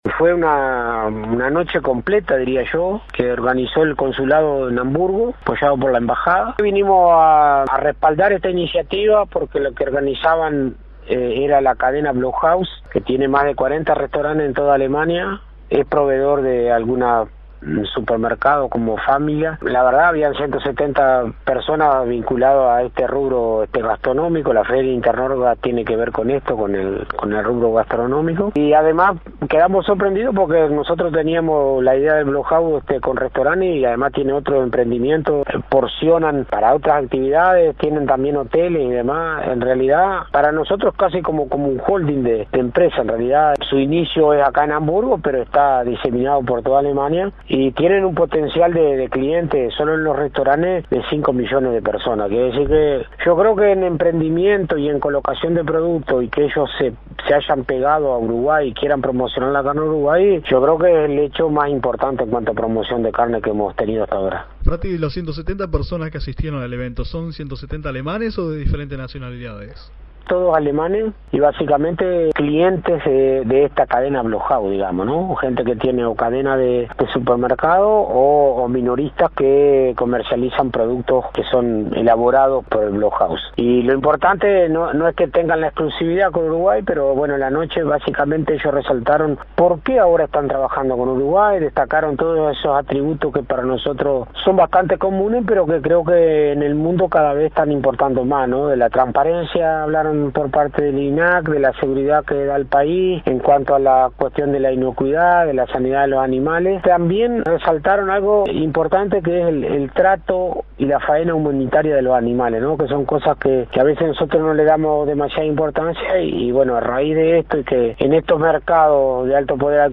AUDIO. Entrevista Fratti.